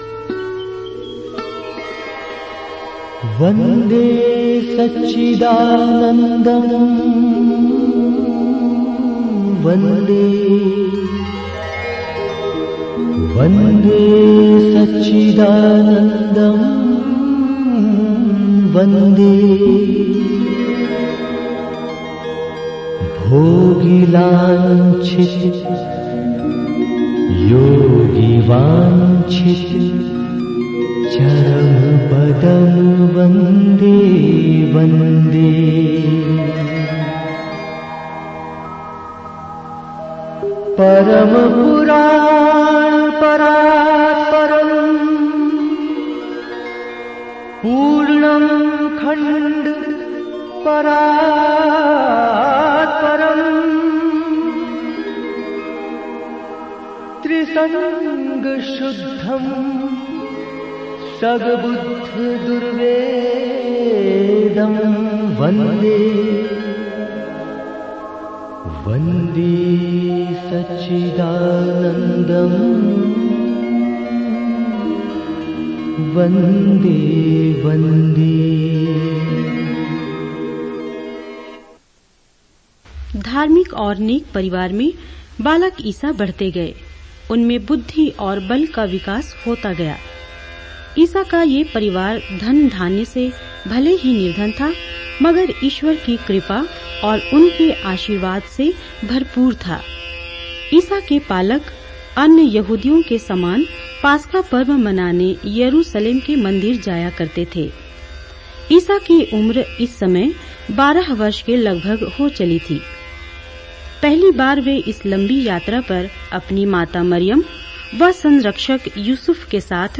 Bible Dramas